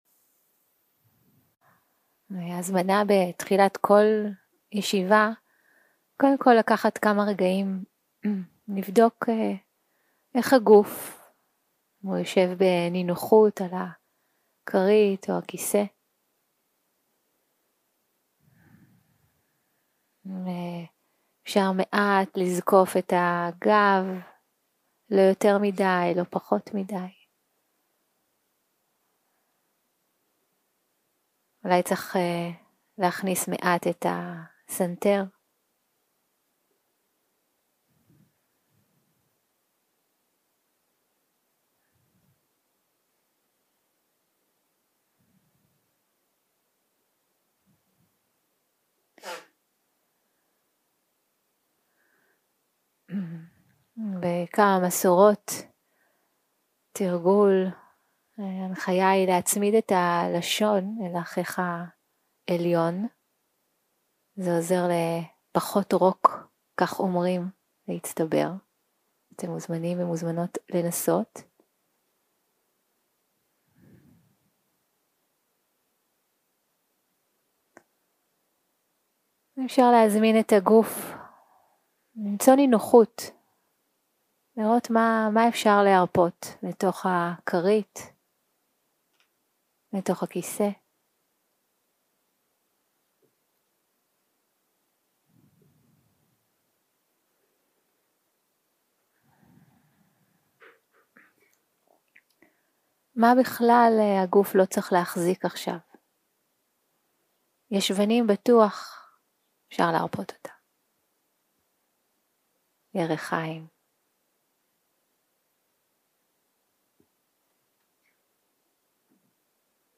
יום 3 - הקלטה 5 - בוקר - הנחיות למדיטציה - דהמה ויצ'יה - חקירת החוויה 0:00 0:00 סוג ההקלטה: Dharma type: Guided meditation שפת ההקלטה: Dharma talk language: Hebrew